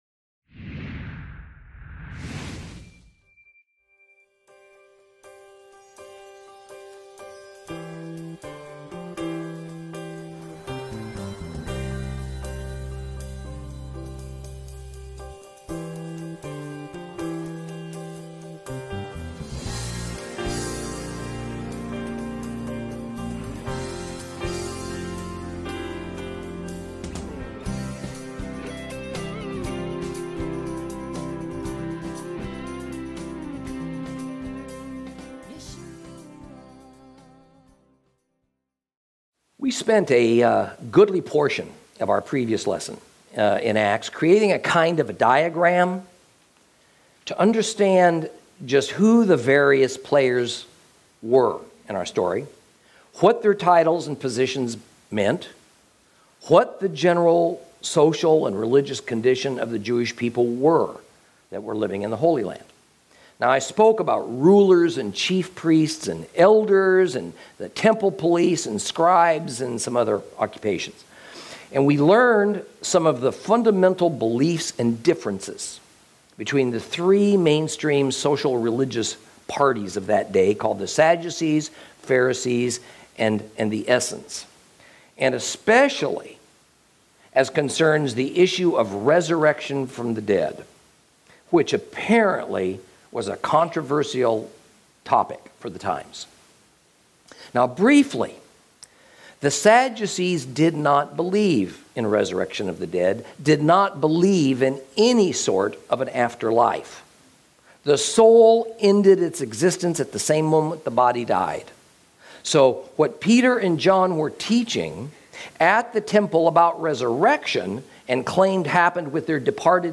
Lesson 11 Ch4 - Torah Class